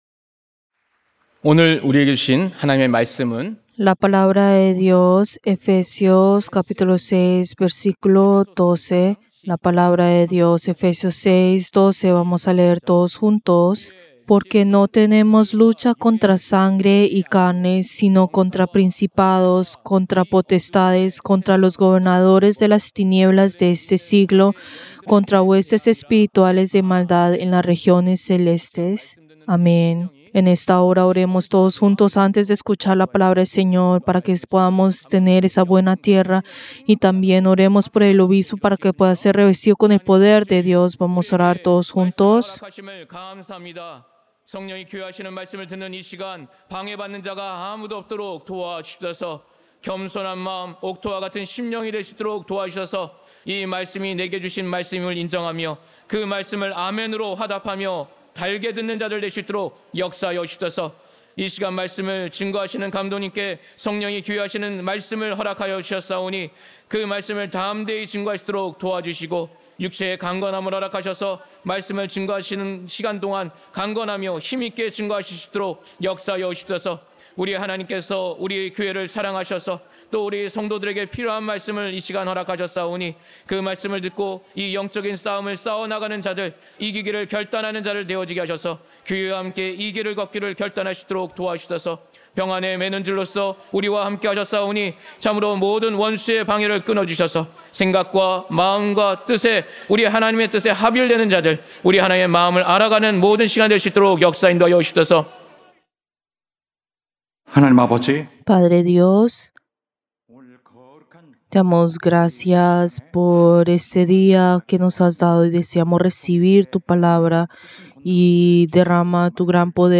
Servicio del Día del Señor del 22 de junio del 2025